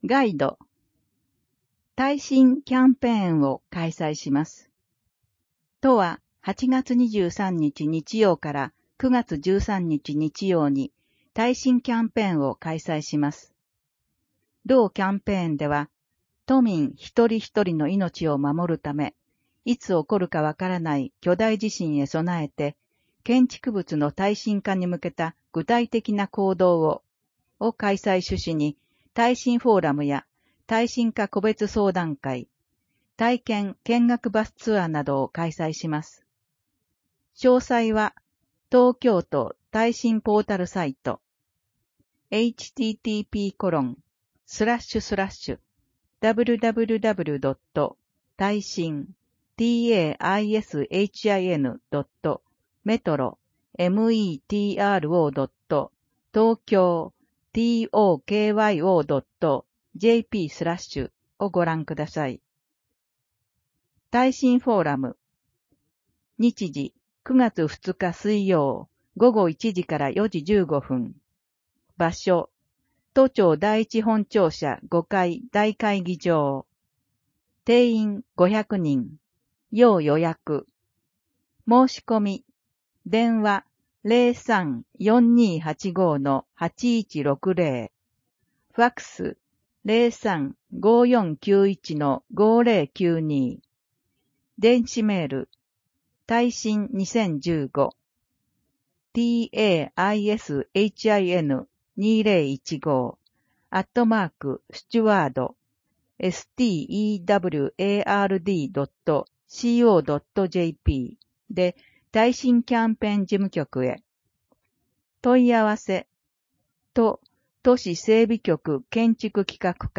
声の広報（平成27年8月15日号）